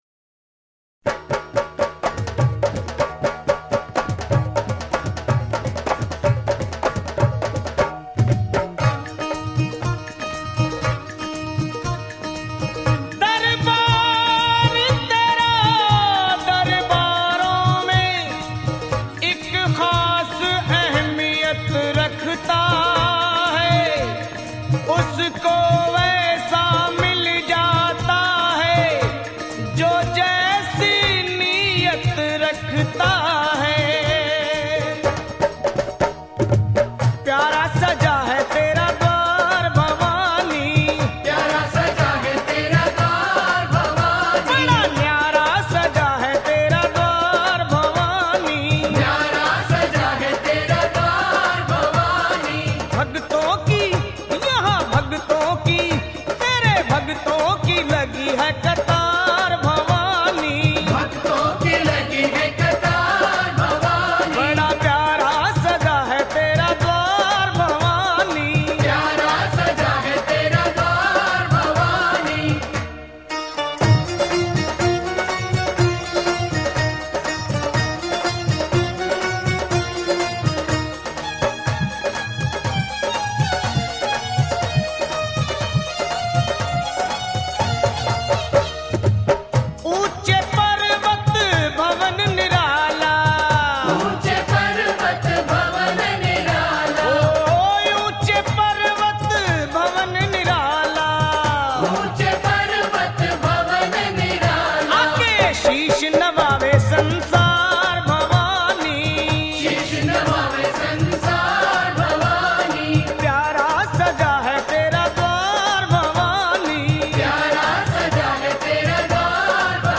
Navratri mata bhajan